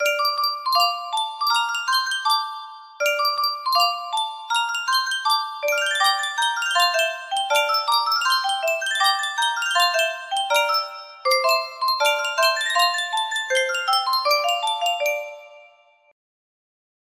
Full range 60